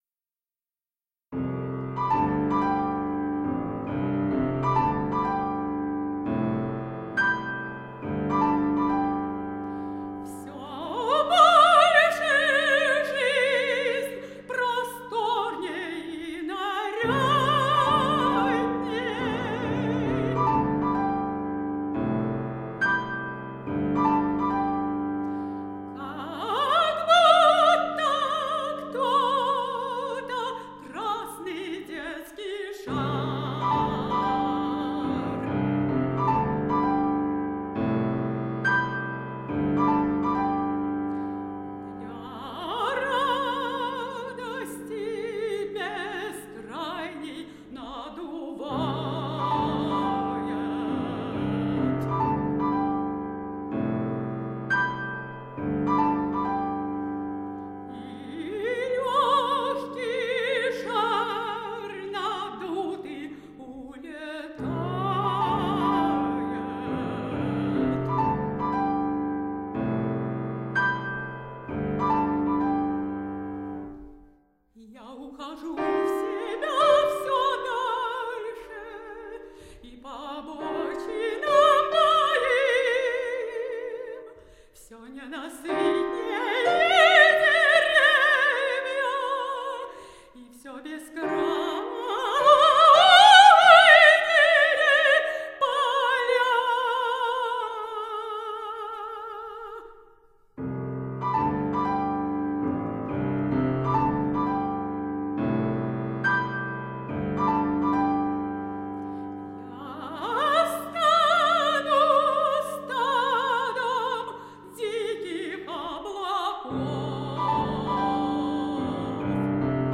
поэмы для голоса и фортепиано